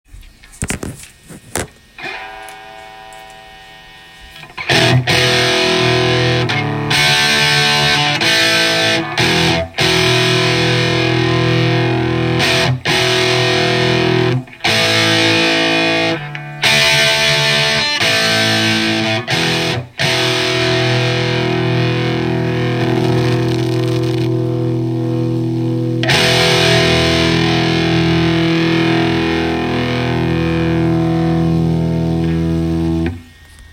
Muffled tone with Marshall Super Lead
Here’s a clip using my phone.
Also the presence and depth controls have very little impact on the tone.
I have Marshall Super Lead 12series cline that sounds muffled through my PS-2A.
The muffled tone will also waver a little louder and softer.